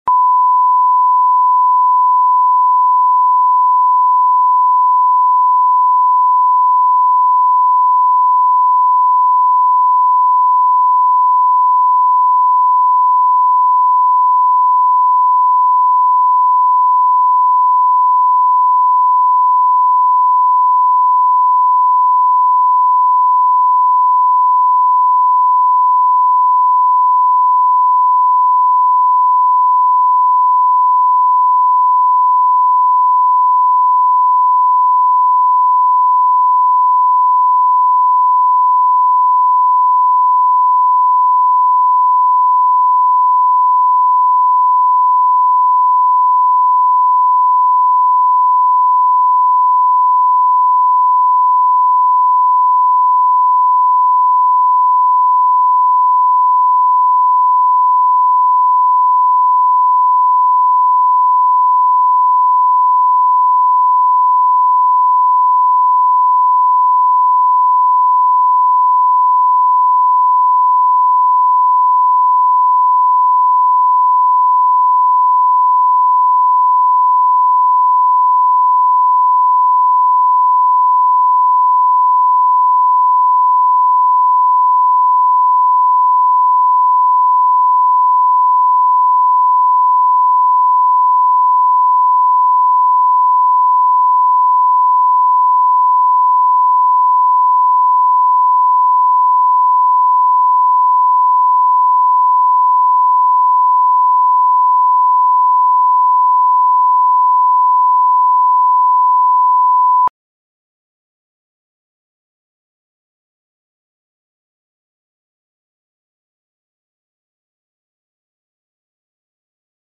Аудиокнига Билет во взрослую жизнь | Библиотека аудиокниг